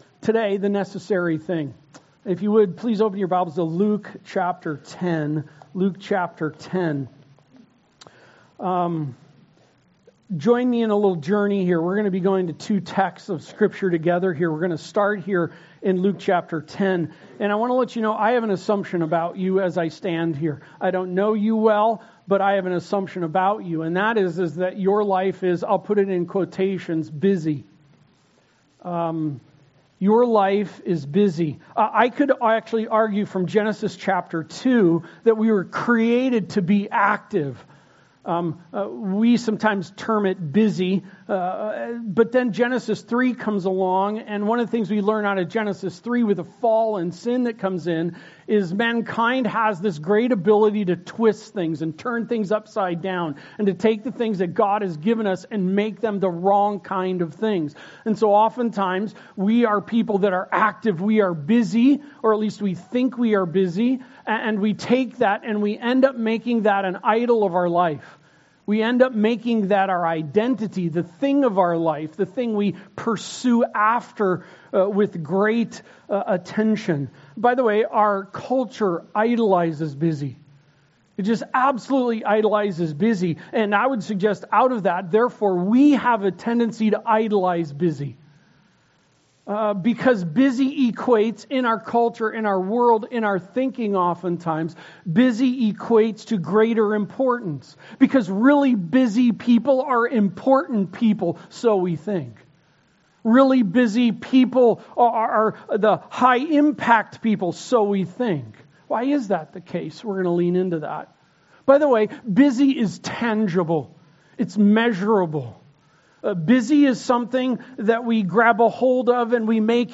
Passage: Luke 10:38-42 Service Type: Sunday Service